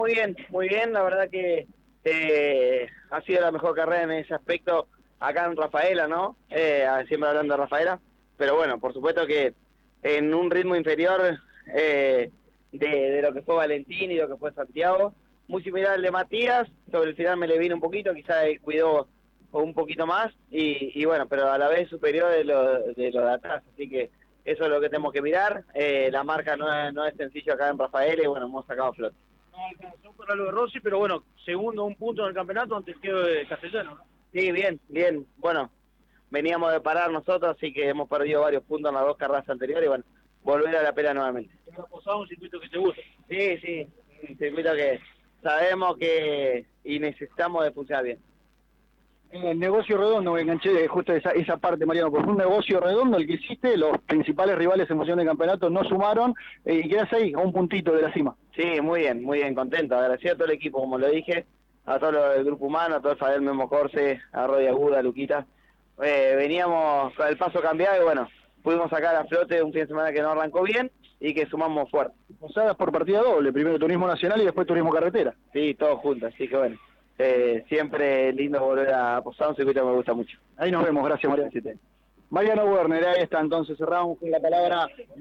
El entrerriano pasó por los micrófonos de Pole Position y habló de la gran cosecha de puntos que obtuvo este fin de semana en Rafaela, que lo posiciona como escolta del campeonato.